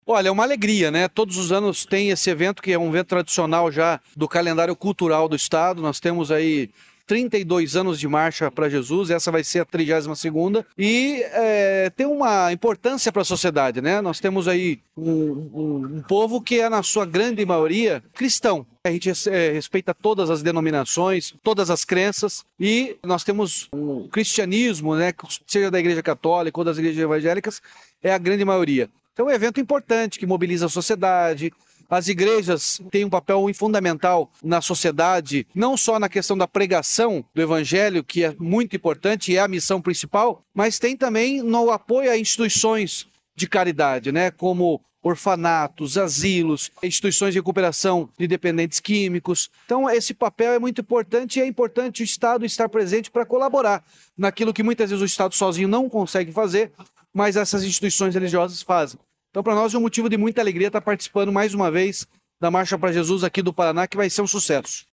Sonora do governador Ratinho Junior sobre a Marcha Para Jesus 2024